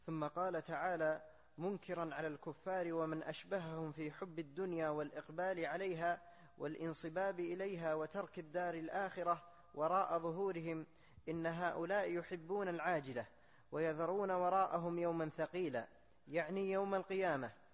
التفسير الصوتي [الإنسان / 27]